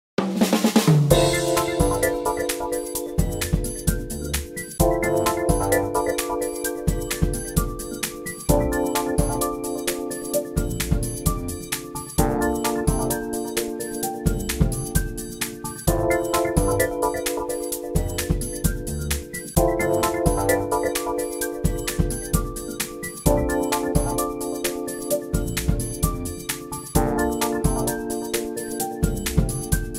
The music that plays on the main menu